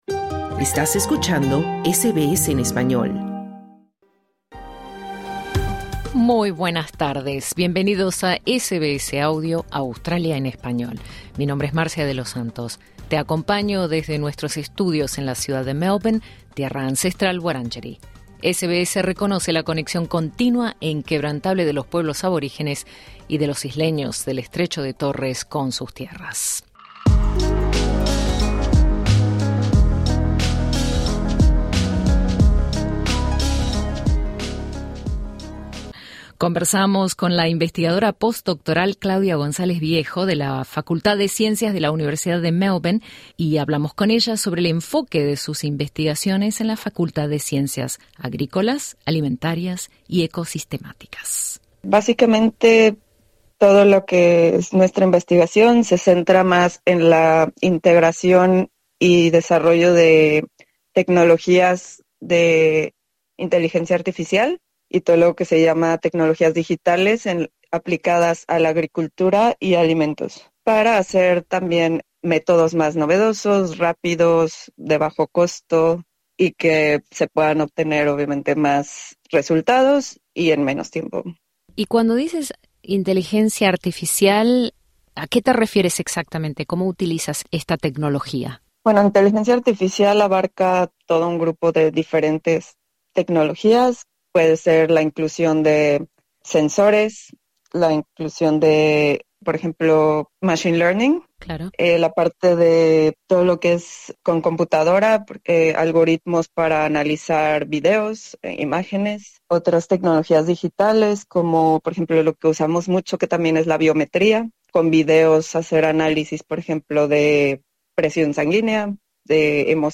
Conversamos con la científica sobre las tecnologías emergentes basadas en inteligencia artificial y su aplicación en la producción de alimentos para el espacio.